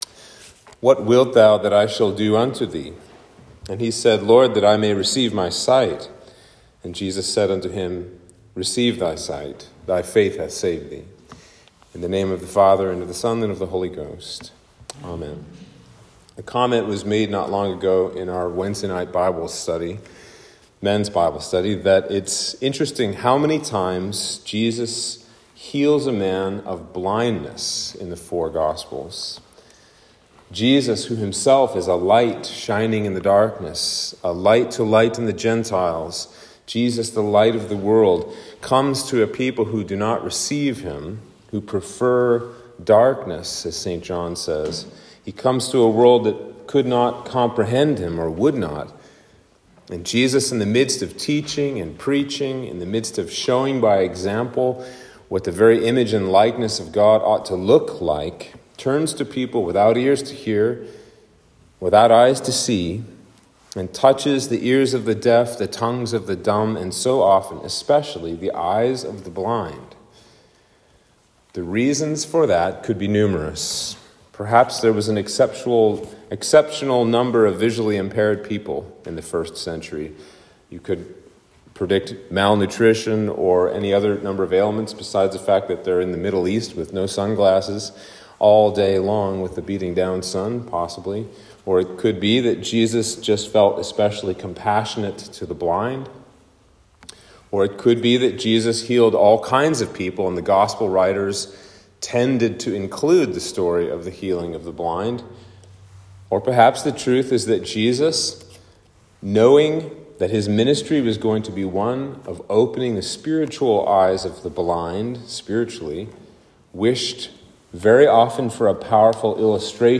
Sermon for Quinquagesima